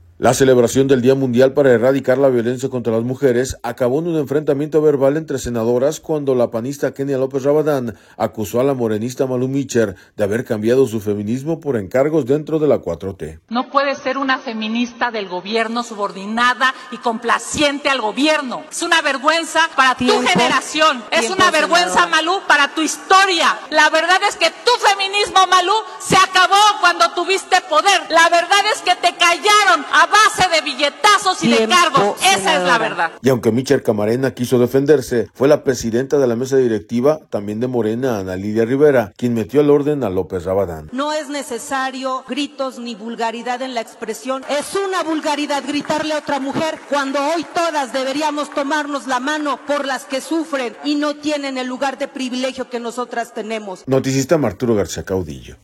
La celebración del Día Mundial para Erradicar la Violencia contra las Mujeres acabó en un enfrentamiento verbal entre senadoras cuando la panista Kenia López Rabadán, acusó a la morenista Malú Micher, de haber cambiado su feminismo por encargos dentro de la 4T.